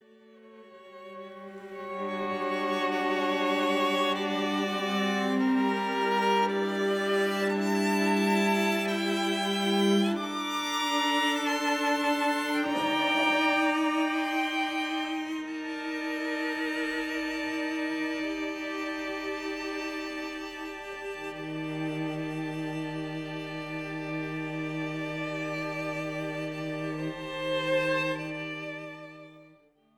adult choir, instrumental ensemble